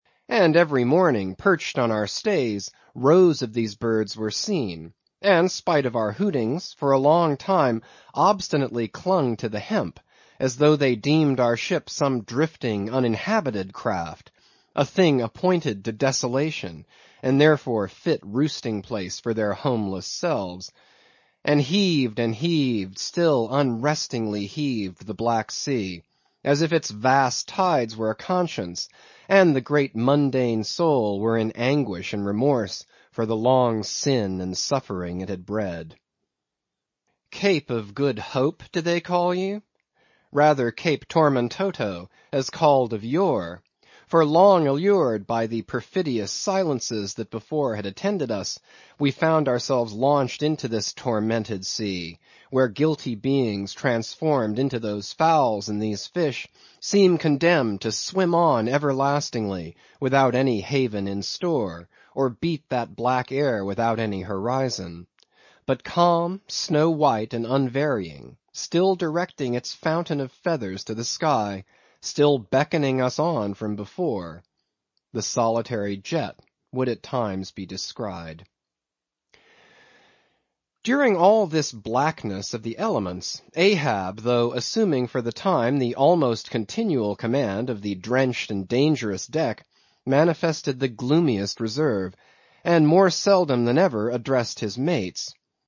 英语听书《白鲸记》第513期 听力文件下载—在线英语听力室